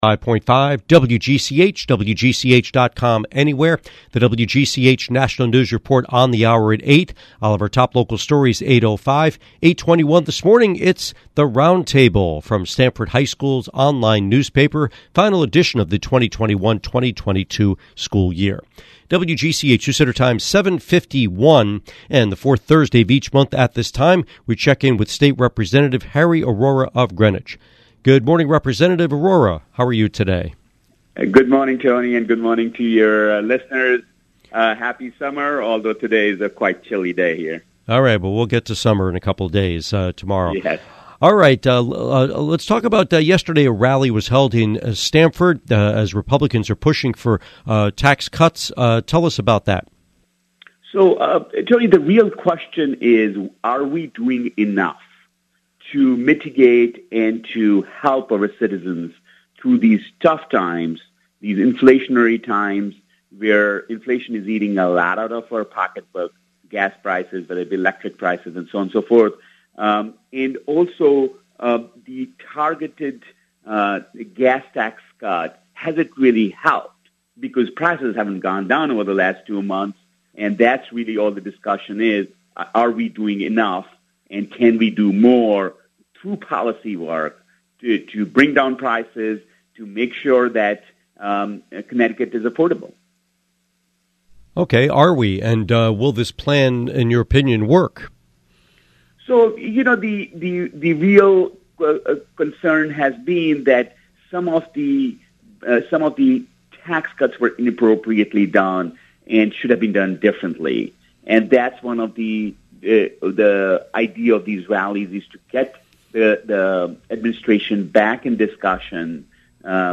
State Representative Harry Arora